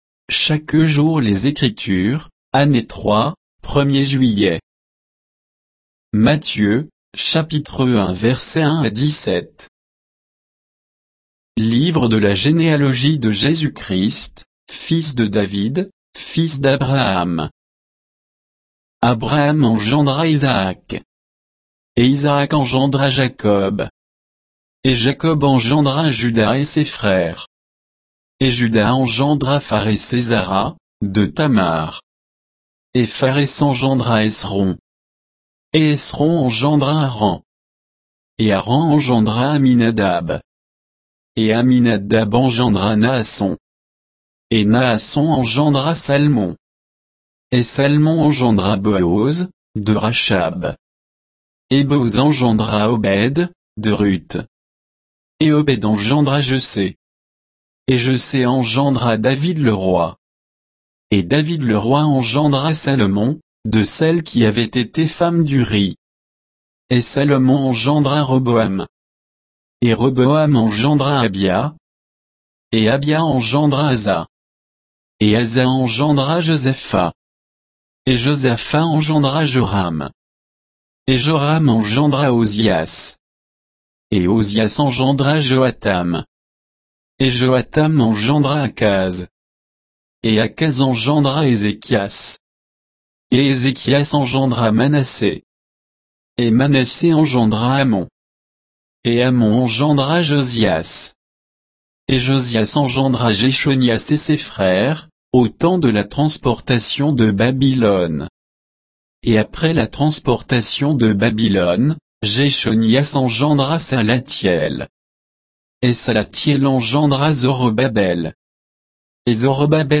Méditation quoditienne de Chaque jour les Écritures sur Matthieu 1